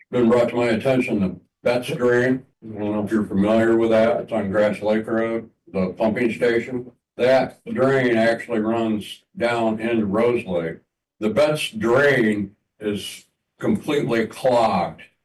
COLDWATER, MI (WTVB) – The Branch County Board of Commissioners heard concerns about low lake levels, weed control and a lack of action on clogged drains from lake residents during the public comment portion of their meeting Tuesday afternoon.